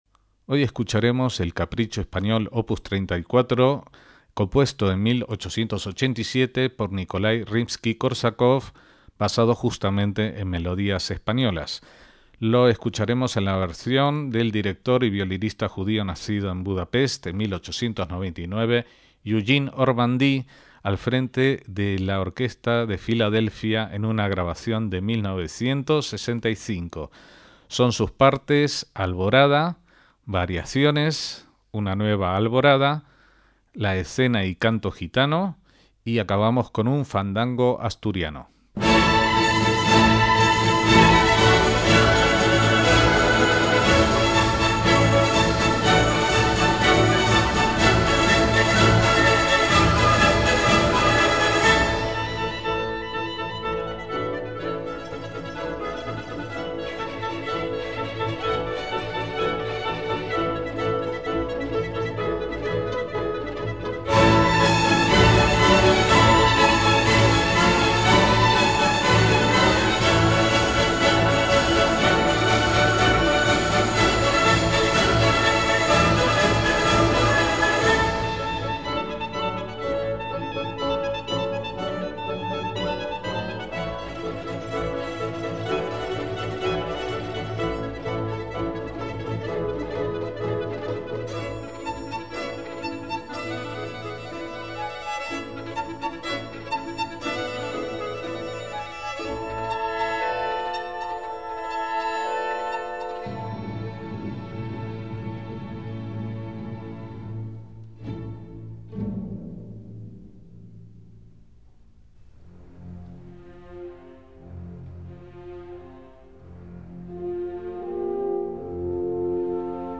MÚSICA CLÁSICA
obra orquestal
basada en melodías españolas
Escena y canto gitano
Fandango asturiano